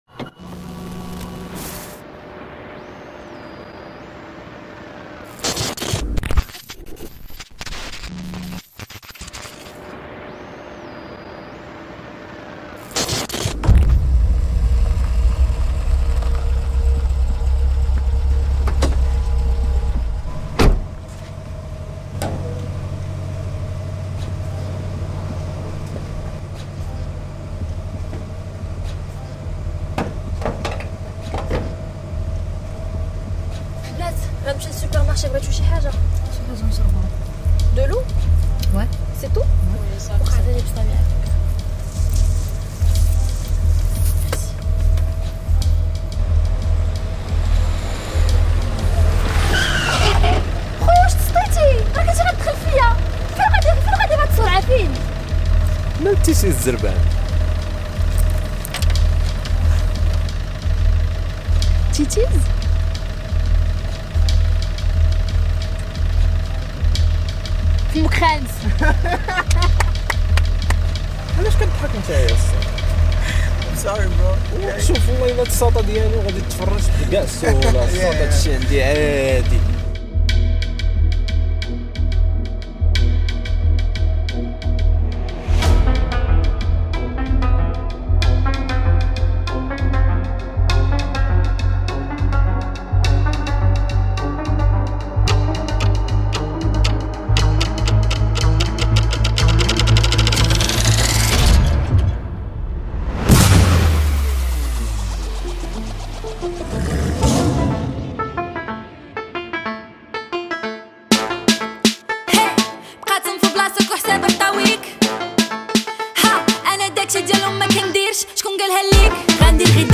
Carpeta: musica arabe mp3